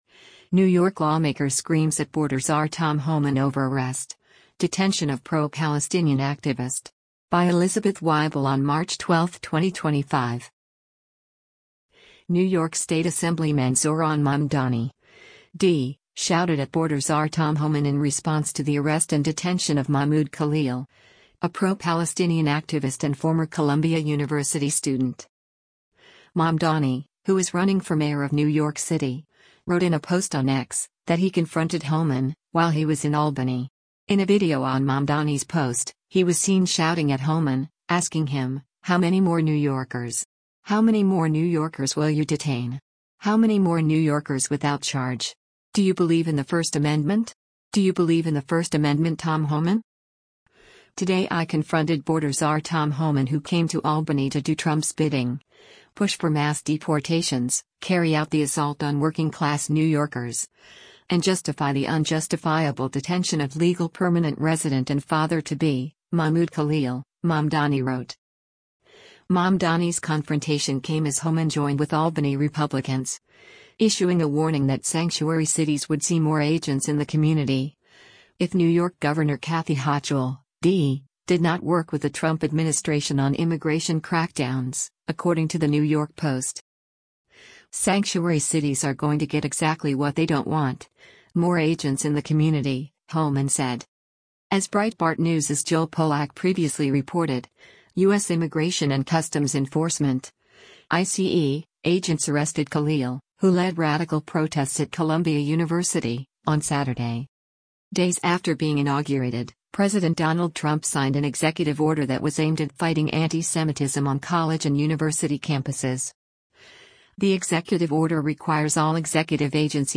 NY Lawmaker Shouts at Tom Homan Over Arrest of Pro-Palestinian Activist
New York Lawmaker Screams at Border Czar Tom Homan Over Arrest, Detention of Pro-Palestinian Activist